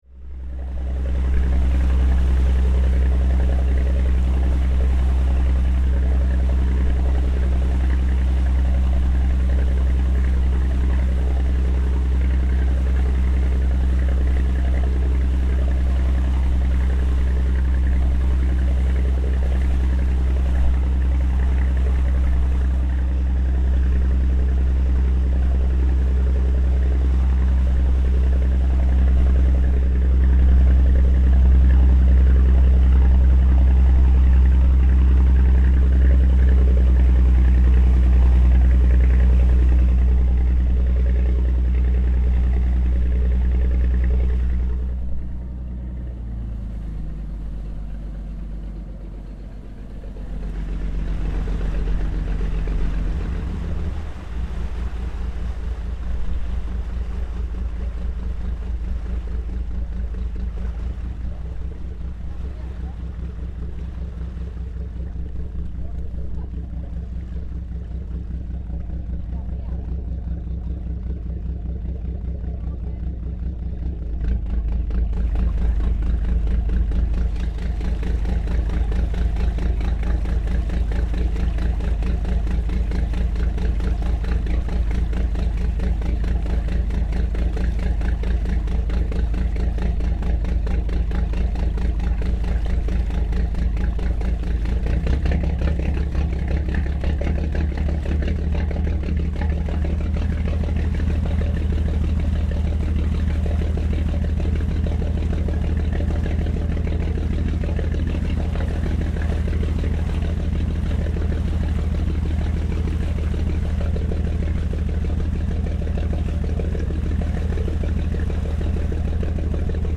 Boat engines roar, Loch Coruisk
Aboard a tourist boat from Loch Coruisk to Elgol, with different tones, speeds and intensity of engine noise blending with the sound of waves.
Recorded on the Isle of Skye by Cities and Memory, April 2025.